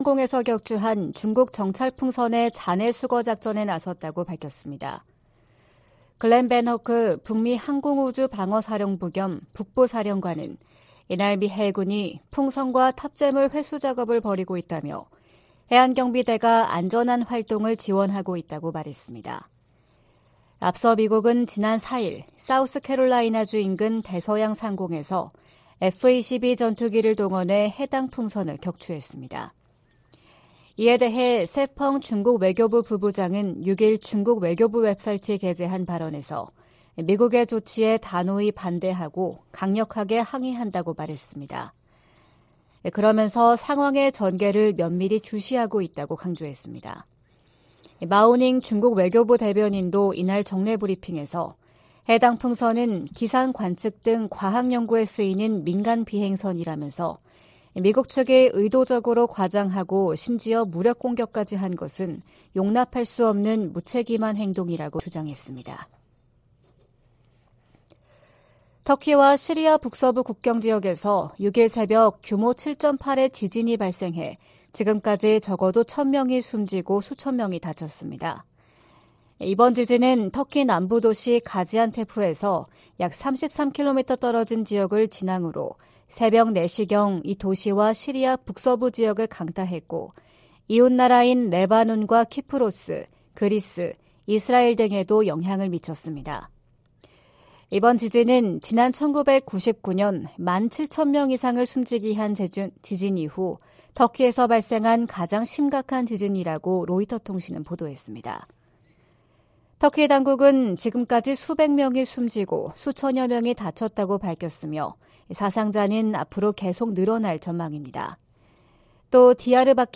VOA 한국어 '출발 뉴스 쇼', 2023년 2월 7일 방송입니다. 워싱턴에서 열린 미한 외교장관 회담에서 토니 블링컨 미 국무장관은, ‘미국은 모든 역량을 동원해 한국 방어에 전념하고 있다’고 말했습니다. 미국과 중국의 ‘정찰 풍선’ 문제로 대립 격화 가능성이 제기되고 있는 가운데, 북한 문제에 두 나라의 협력 모색이 힘들어질 것으로 전문가들이 내다보고 있습니다.